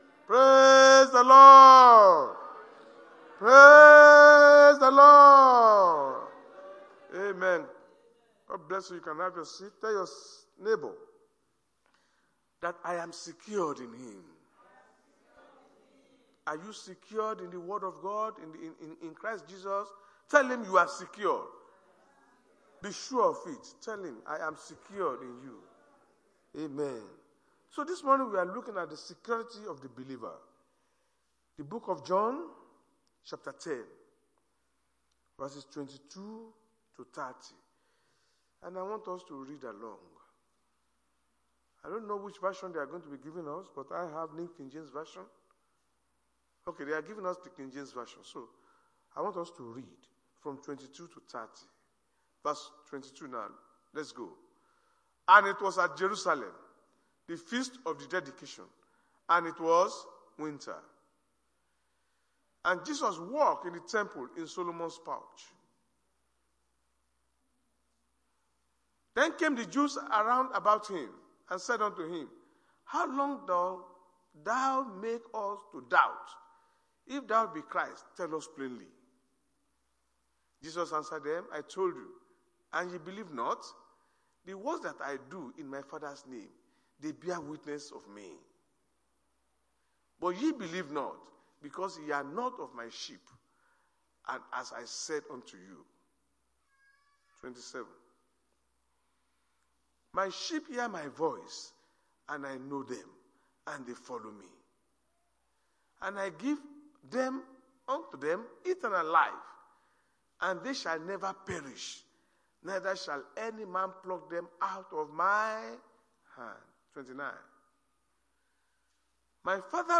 Sunday Sermon-The Security Of The Believer